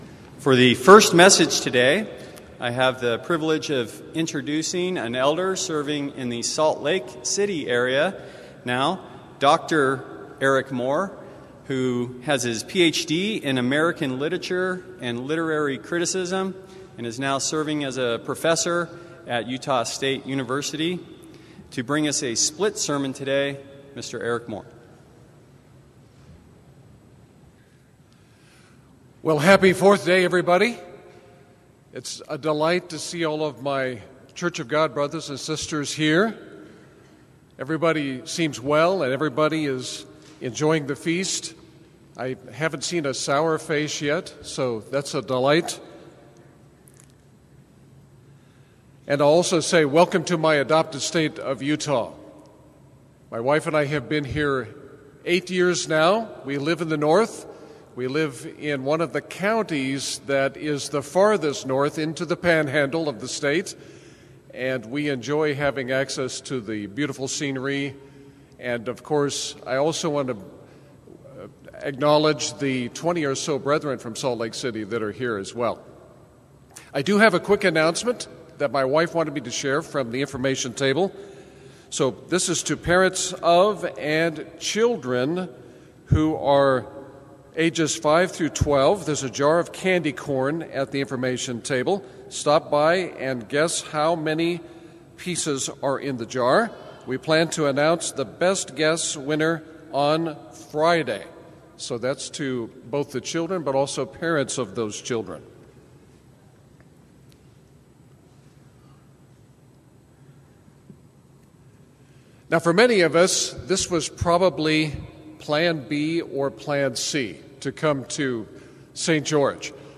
This sermon was given at the St. George, Utah 2020 Feast site.